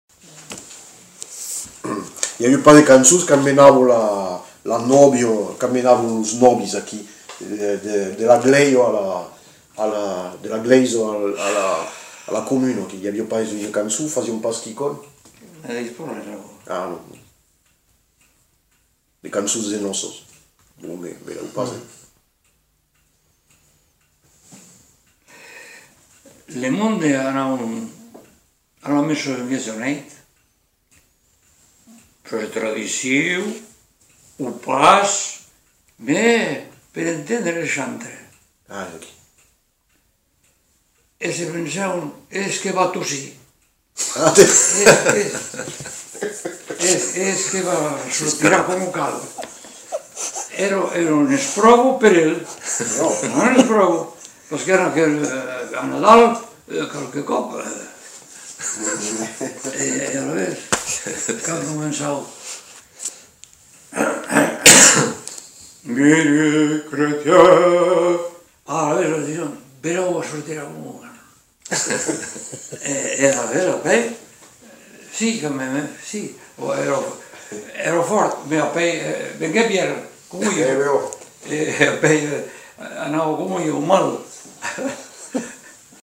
Lieu : Le Faget
Genre : témoignage thématique